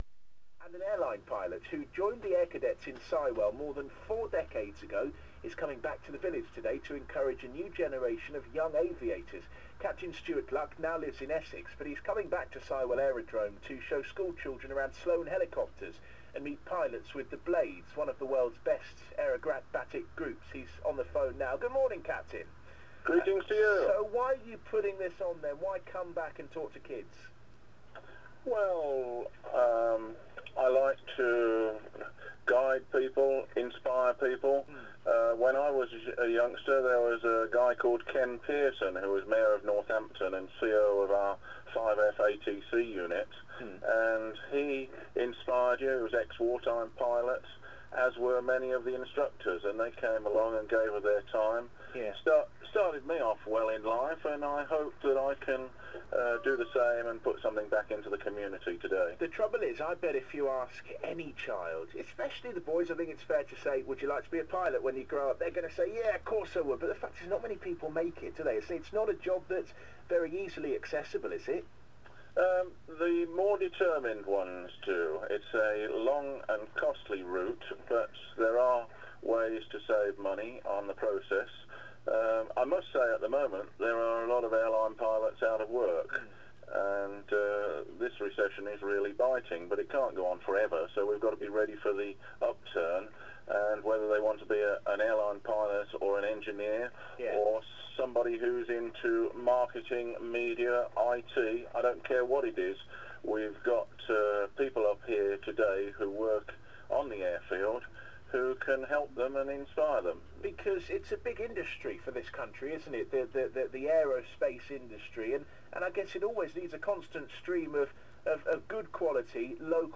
Audio interview on BBC Radio Northampton